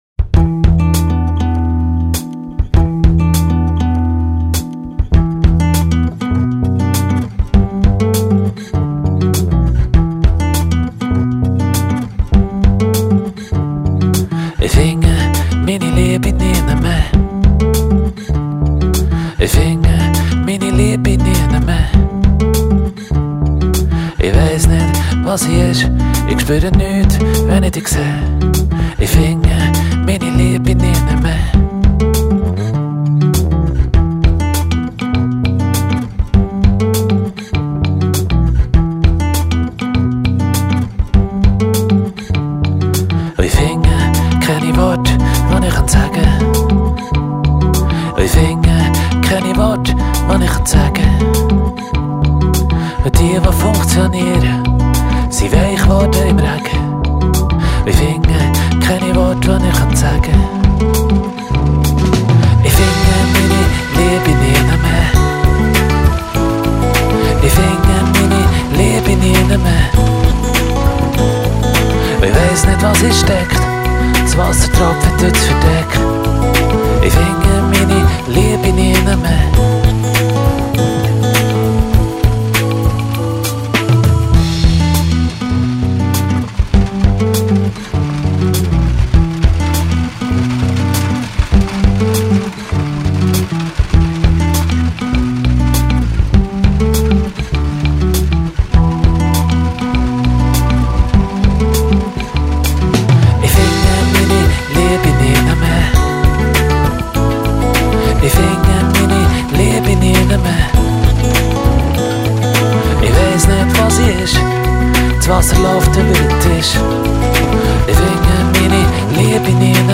rock combo